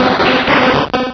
cries
-Replaced the Gen. 1 to 3 cries with BW2 rips.